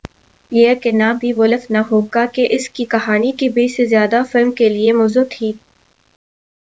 deepfake_detection_dataset_urdu / Spoofed_TTS /Speaker_16 /202.wav